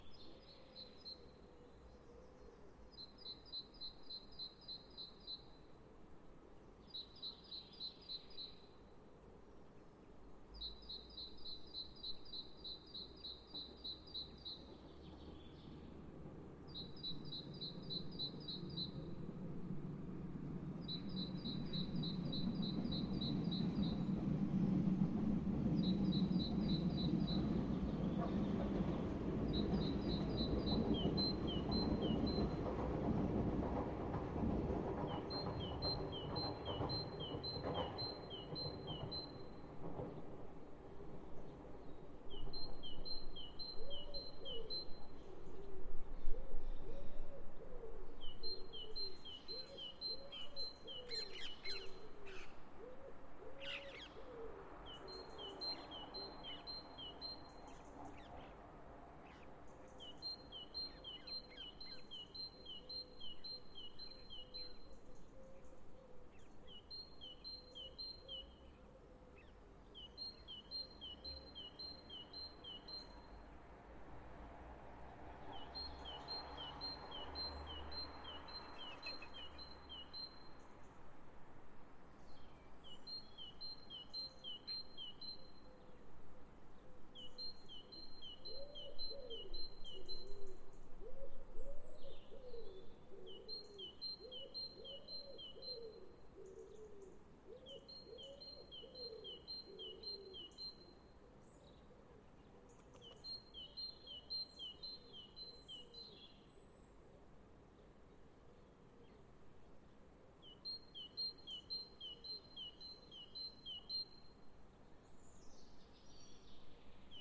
Dans ce coin de Versailles aux antipodes du monde polic� du Ch�teau on entend chanter les oiseaux qui dialoguent, matin et soir, avec leurs amis les trains
L'enregistrement fait t�t le matin samedi 20 mars 2021 � Porchefontaine.
shortbirds.mp3